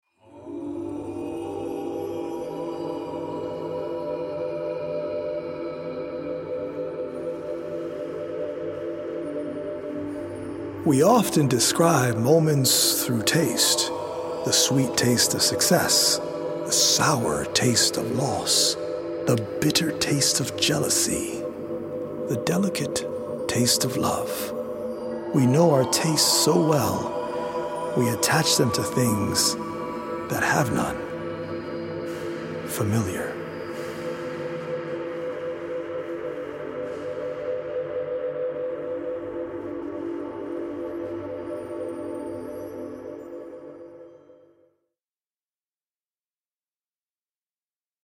Senses is a healing audio-visual poetic journey through the mind-body and spirit that is based on 100 original poems written/performed by
healing Solfeggio frequency music